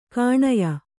♪ kanye